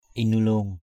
/i-nɯ-lo:ŋ/ (d.) voi chúa = reine éléphante. elephant the head.